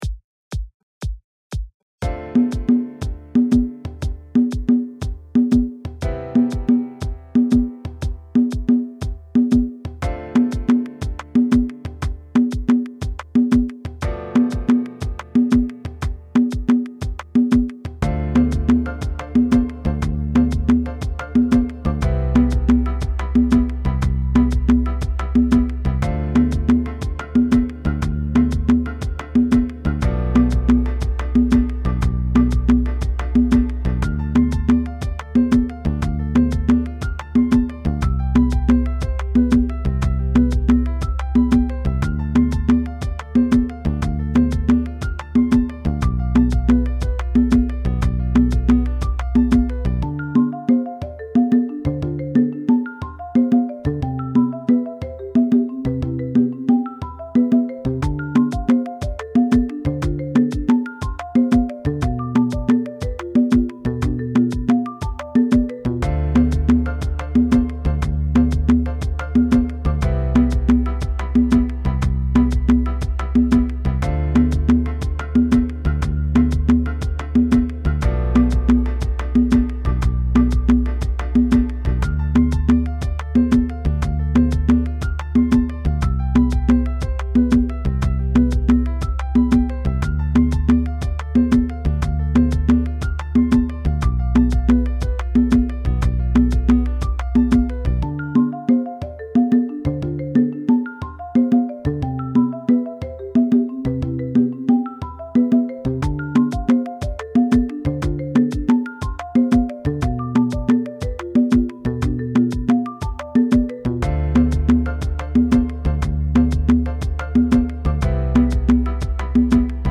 Afro-House inspired mix tracks